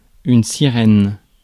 Ääntäminen
Ääntäminen US : IPA : [ˈvık.sən]